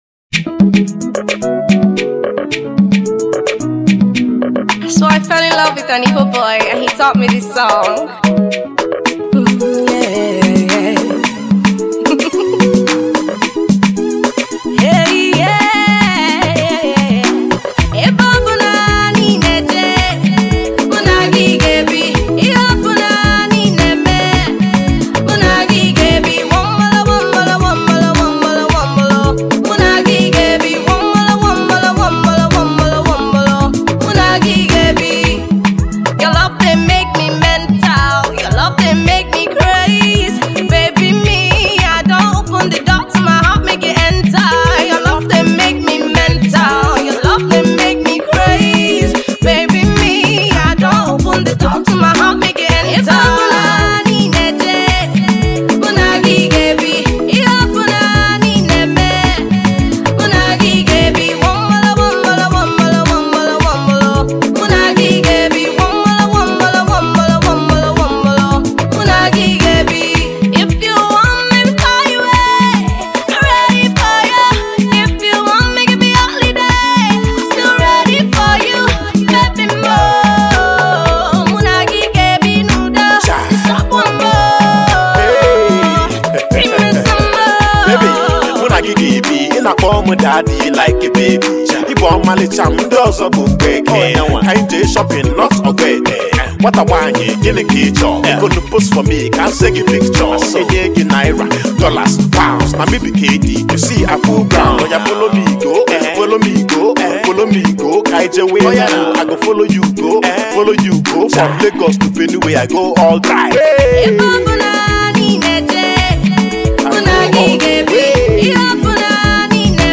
more ethnic